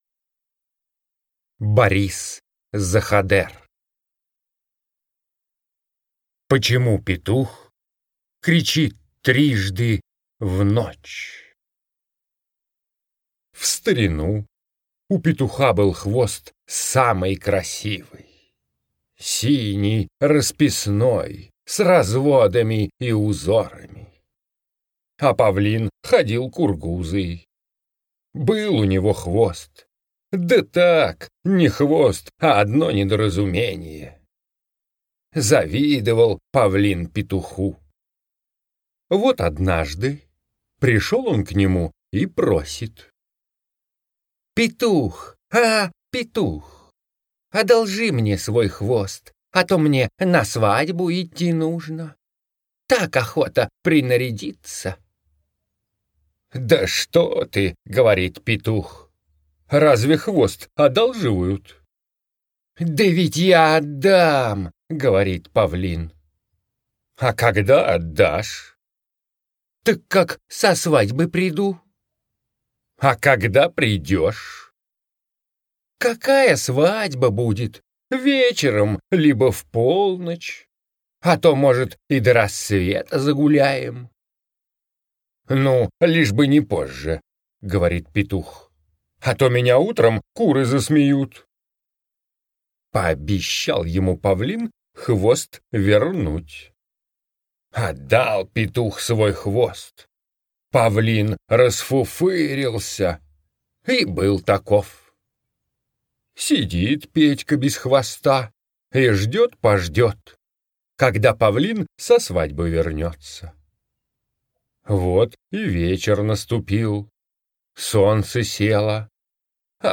Слушайте Почему петух кричит трижды в ночь - аудиосказка Заходера Б. Как Павлин обманул Петуха и забрал у него красивый хвост.